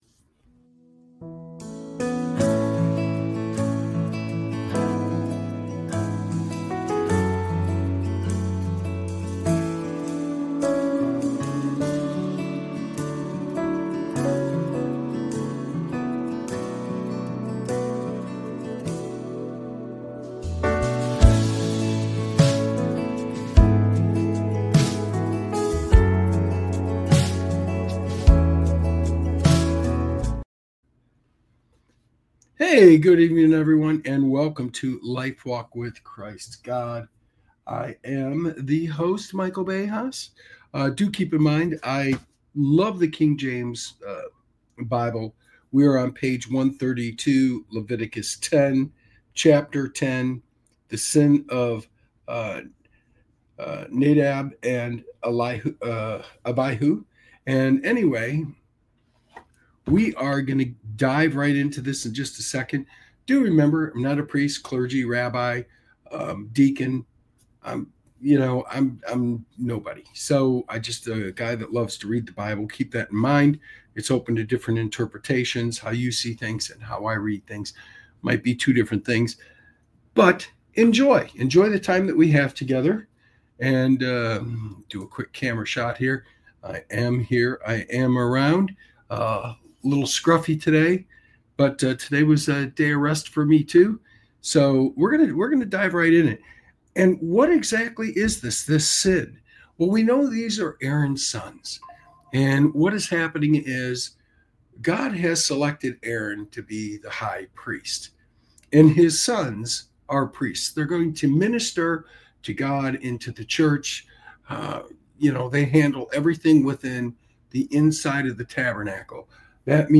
This show offers a reading into the teachings of Jesus Christ, providing insights into the Bible. Through engaging readings, heartfelt testimonies, and inspiring messages, Lifewalk with Christ God aims to: Strengthen faith: Explore the power of prayer, worship, and devotion.